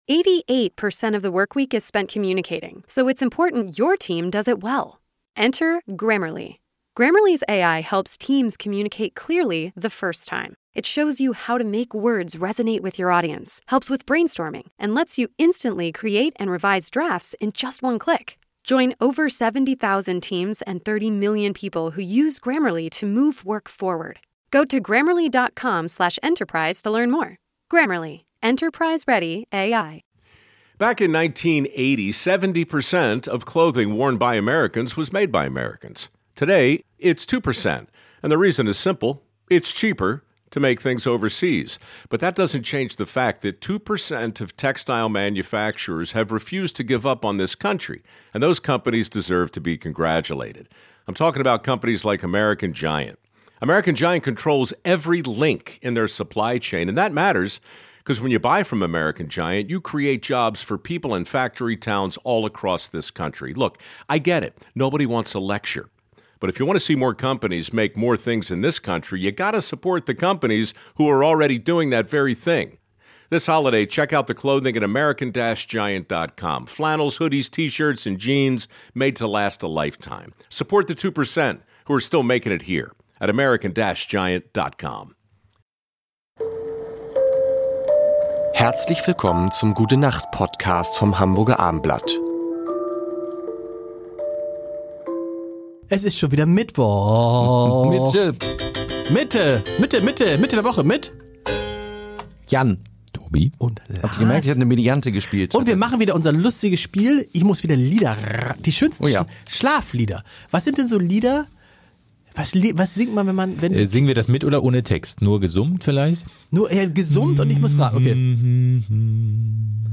Gute Nacht, Schlaflieder